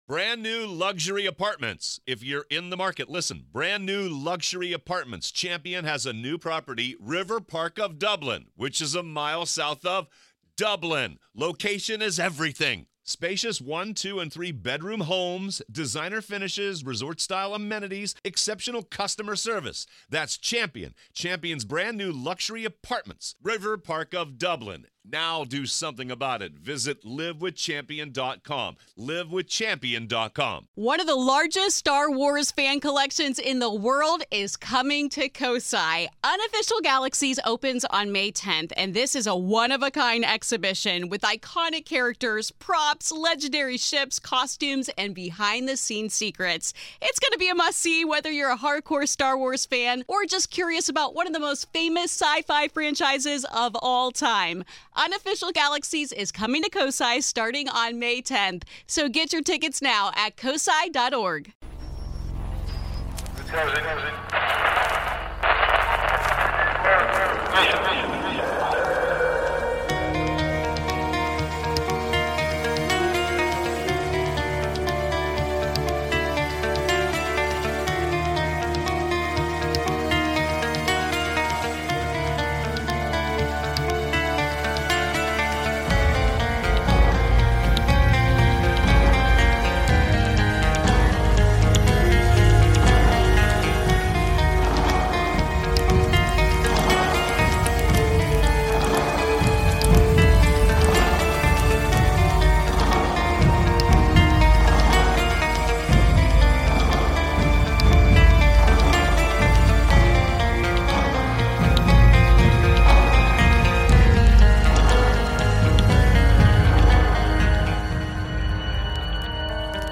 Tonight we hear several calls about ghostly experiences ranging from deathbed visitors to the infamous hatman.